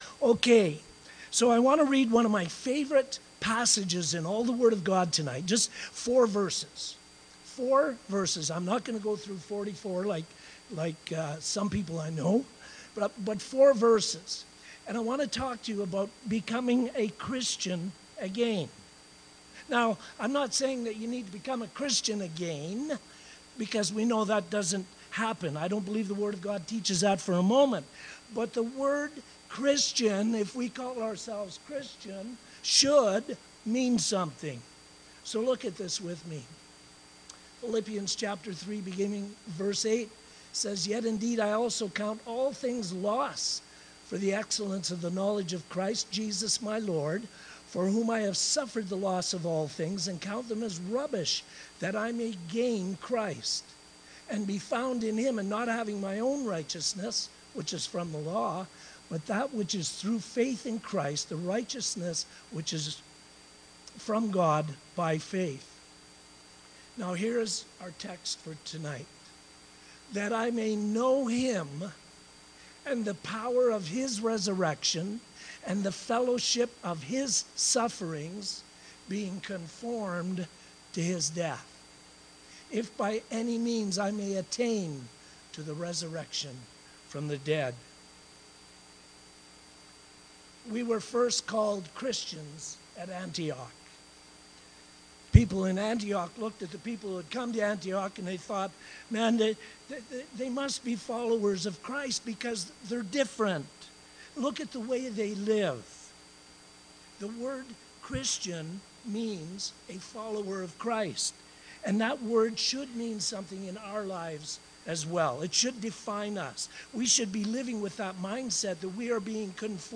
Testimony